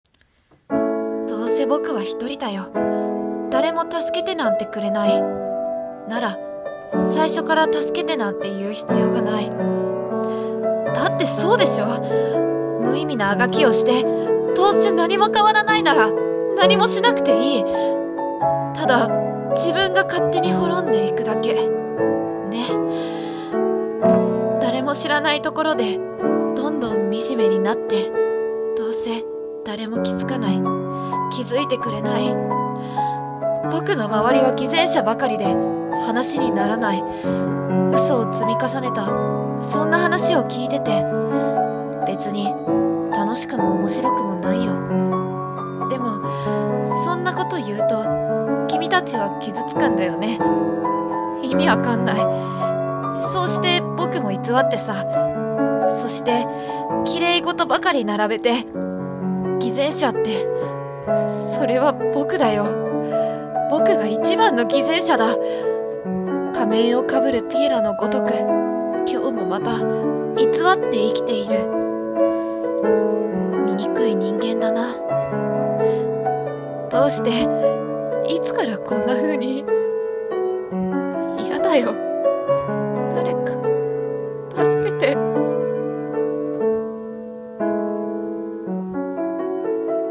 【病み声劇】